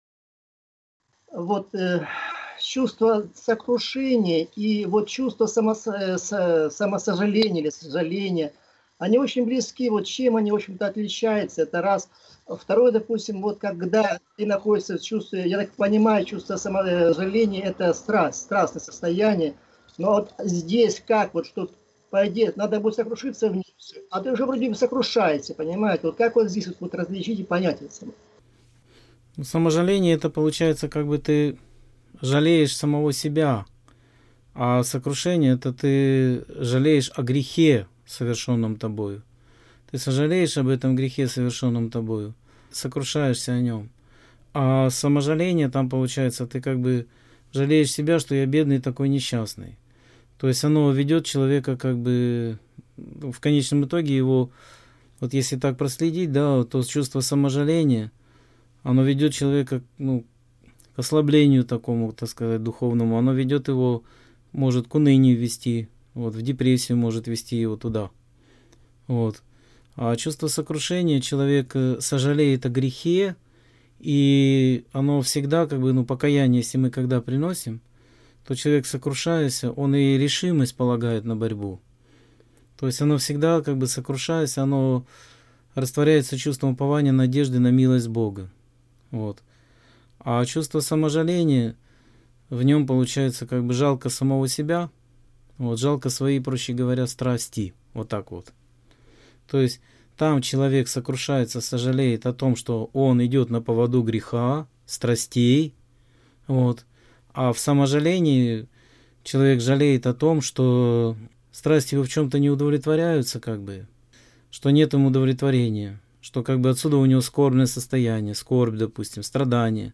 Скайп-беседа 3.06.2017